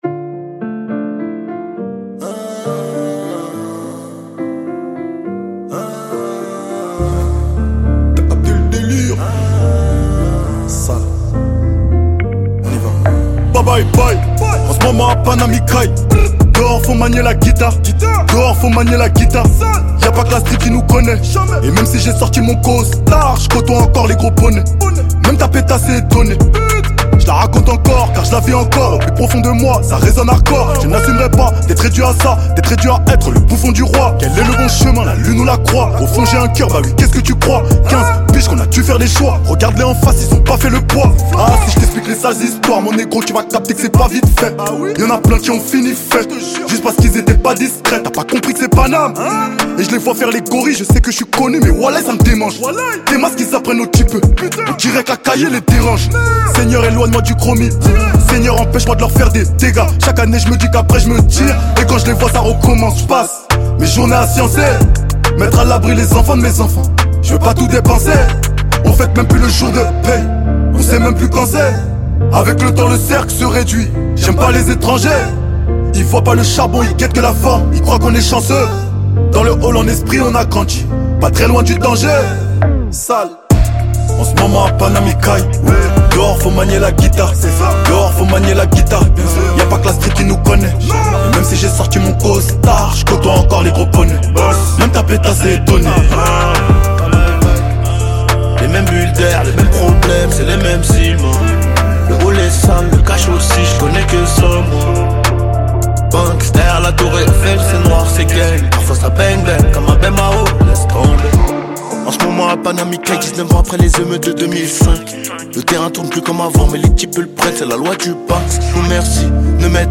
30/100 Genres : french rap Télécharger